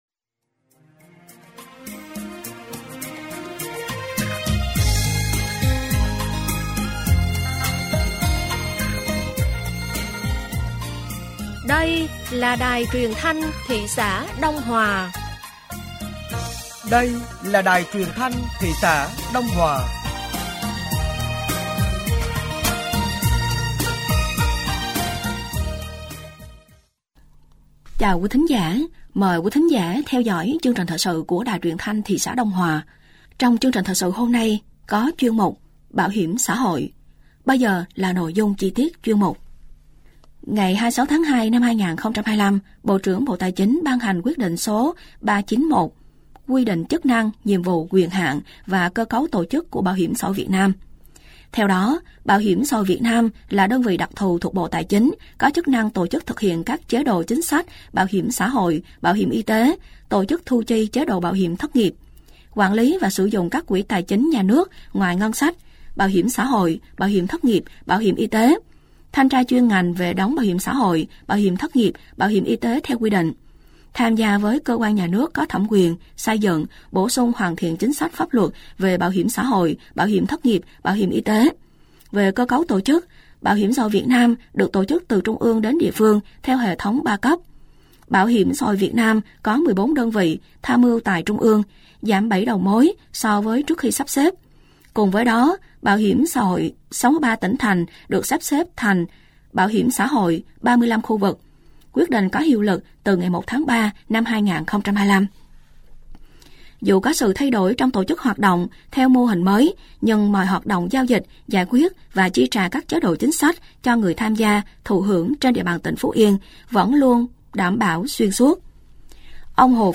Thời sự tối ngày 12 và sáng ngày 13 tháng 4 năm 2025